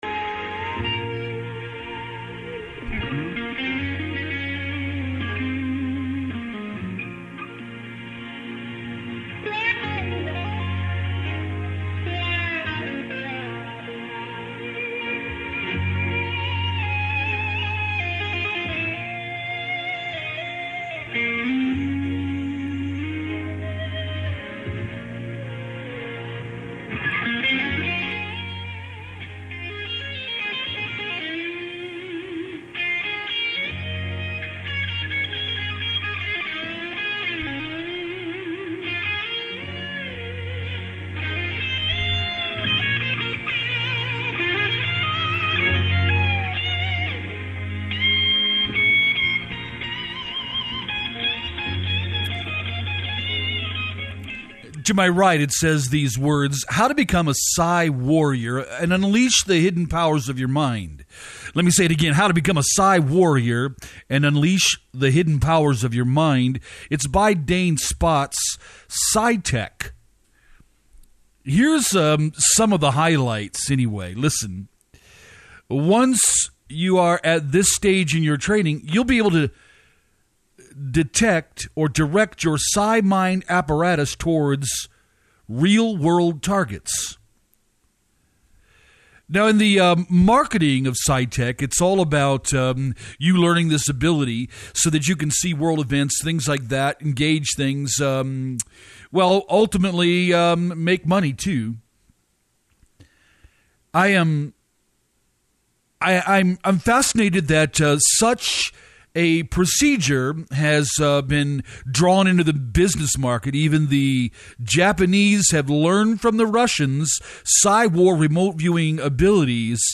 SHATTER LIVE RADIO JUNE 20TH TO 24TH 2011 ASTRAL PROJECTION ASTRAL ATTACK PSYCHIC ASSASSINATIONS WEAPONIZING ASTRAL PROJECTION THURSDAY JUNE 23 SHATTER LIVE RADIO BROADCAST 6PM Astral projection, �weaponizing� remote viewing and the secrets of the sorcerers in attacking, harming and killing their targets.